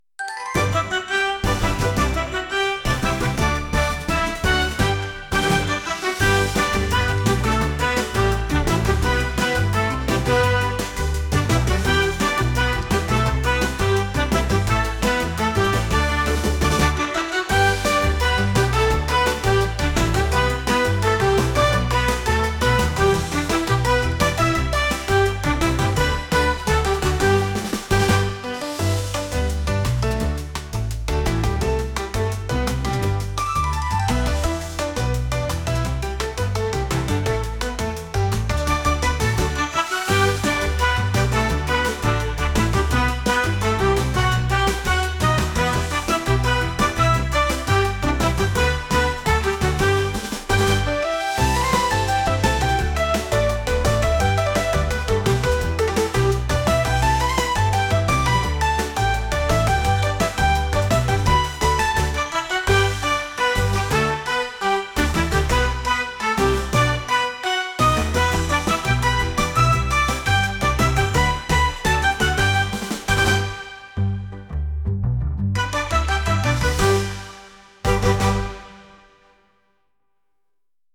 誰かを元気づけたい勢いのある音楽です。